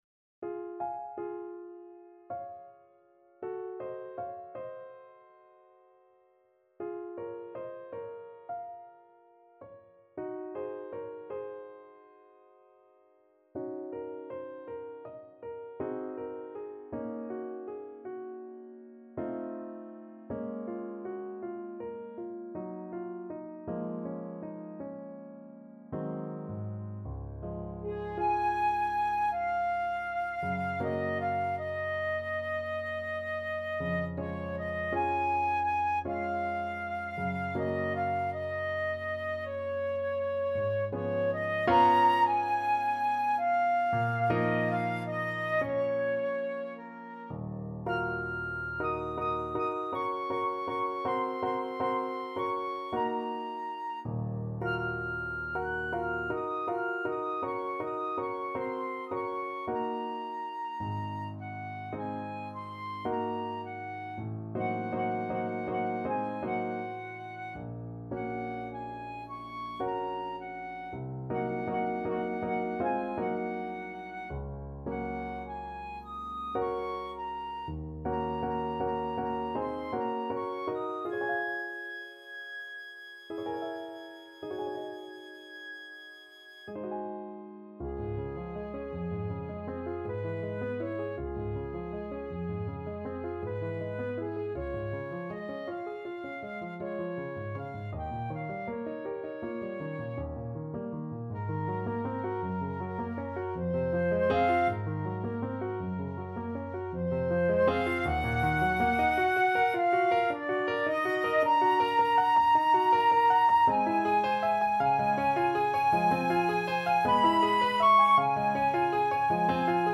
Classical Debussy, Claude Clair De Lune Flute version
FlutePiano
Andante tres expressif
9/8 (View more 9/8 Music)
Db major (Sounding Pitch) (View more Db major Music for Flute )
Classical (View more Classical Flute Music)
Relaxing Music for Flute
Nostalgic Music for Flute